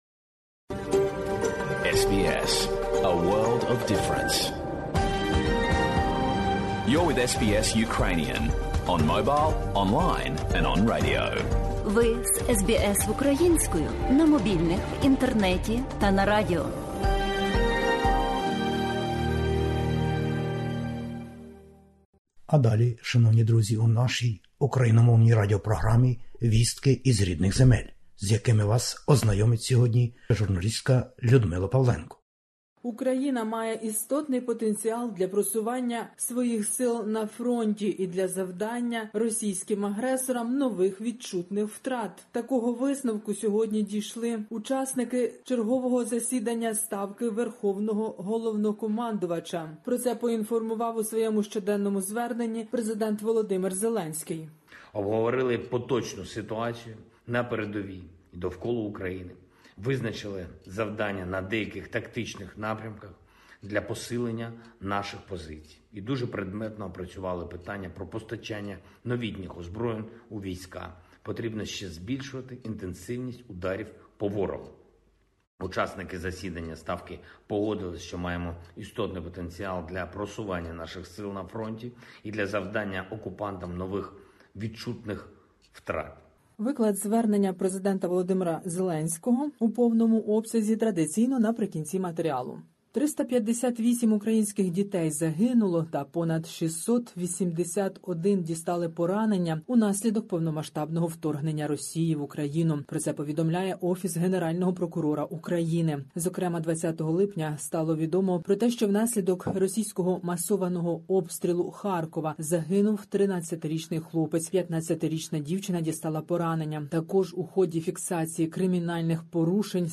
Добірка новин із героїчної України. Війна в Україні - Україна «має істотний потенціал» для просування на фронті,-повідомив Президент України. 358 дітей загинули і 681 дитина поранена від обстрілів і нападу російських військ.